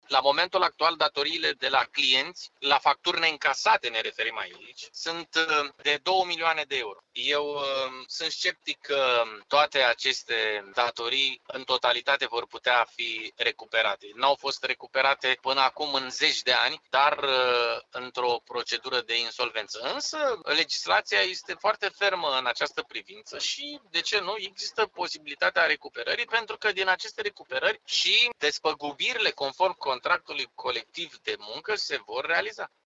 Declarația a fost făcută de primarul municipiului Tulcea, Ștefan Ilie.